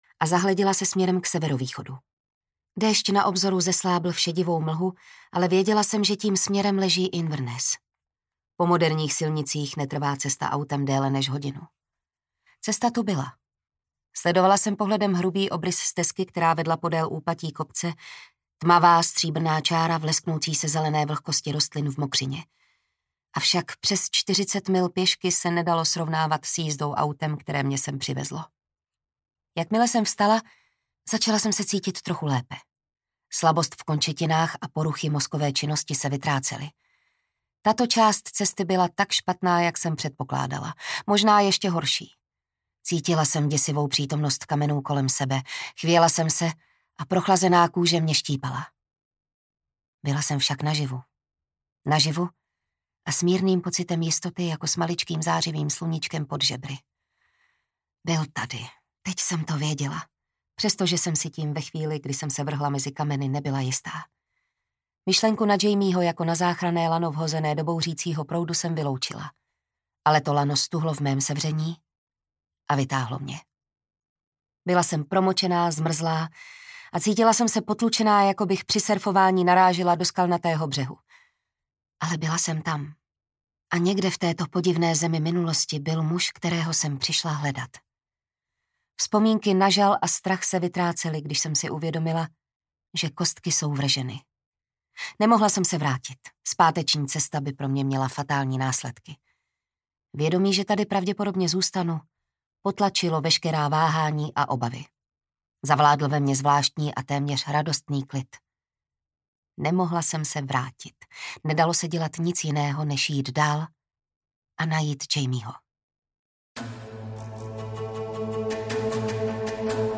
Mořeplavec audiokniha
Ukázka z knihy